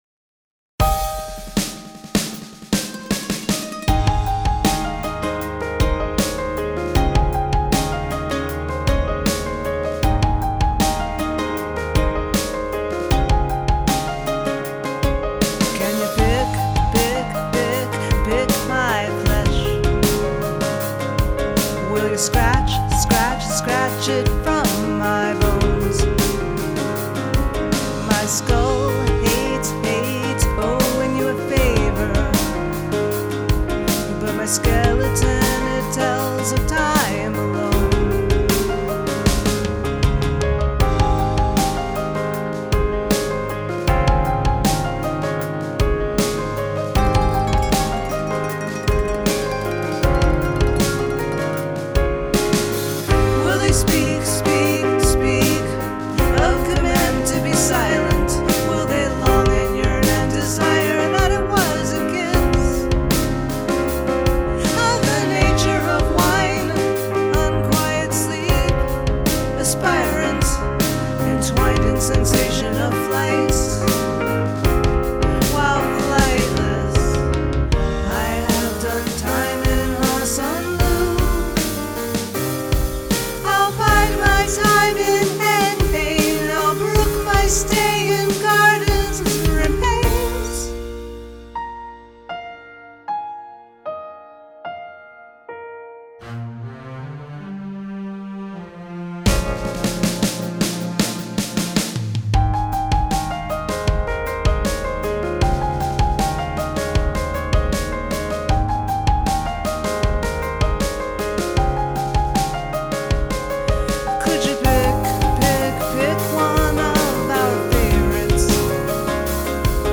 Love the use of repetition here!